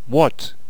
archer_select6.wav